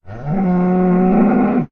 cow_say4.ogg